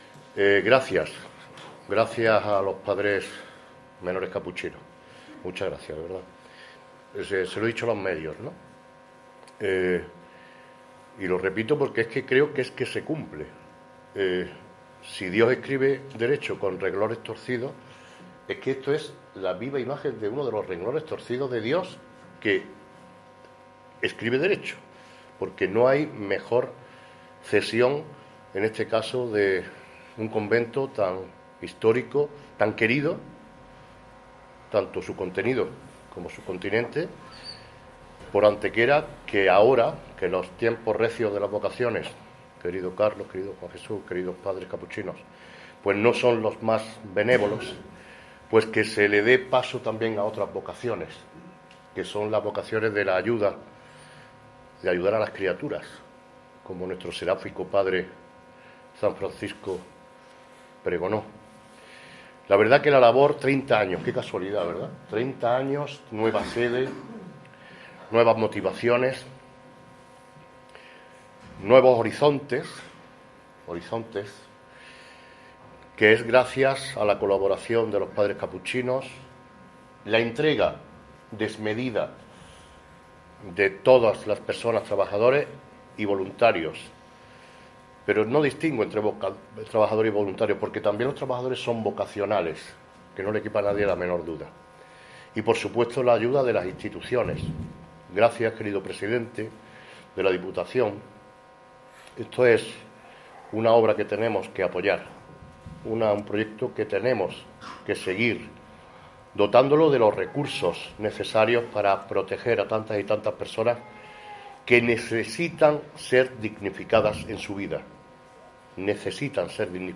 El alcalde Manolo Barón asiste a la inauguración de la nueva sede de la asociación Resurgir Proyecto Humano ubicado en el antiguo convento de los Capuchinos
Cortes de voz